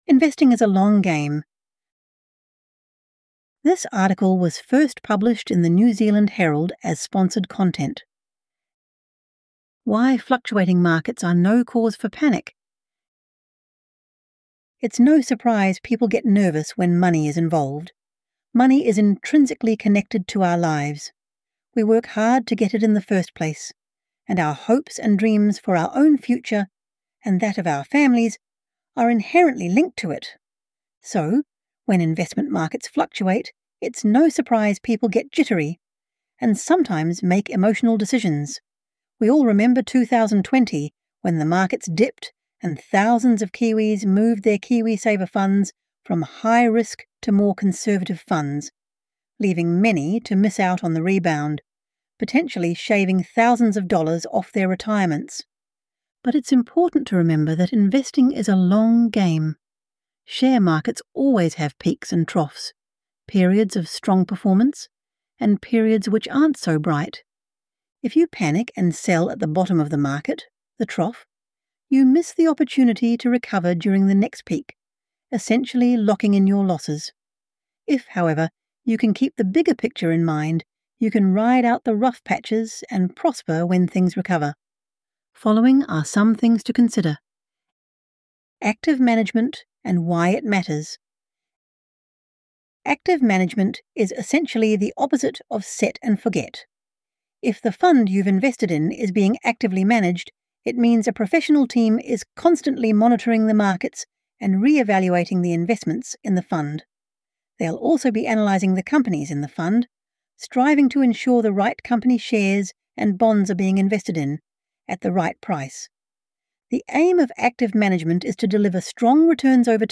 This content features an AI-generated voice for narration purposes.